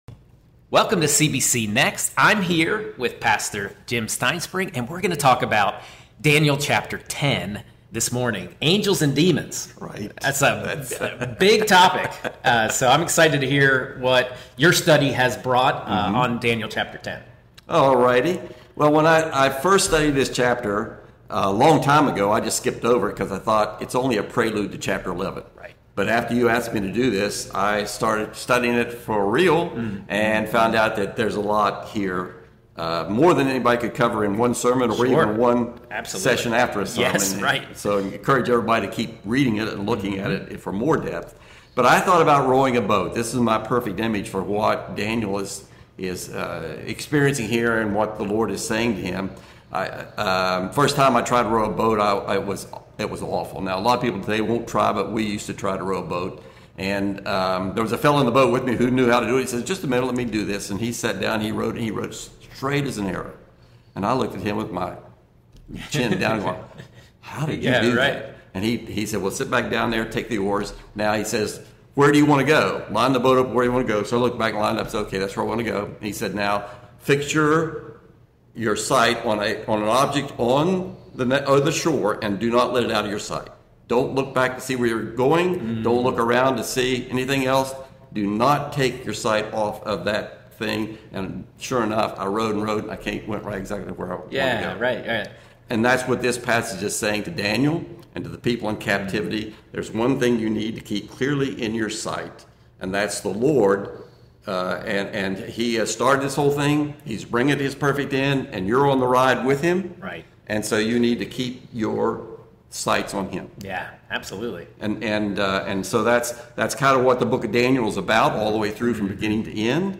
We started a 12-week series through the book of Daniel with a focus on how to live as Strangers in this world. As a way to dive deeper in this theologically rich book, we will be posting a follow up conversation on Facebook and YouTube every Tuesday at 6 with some guests that will bring a different perspective on Daniel.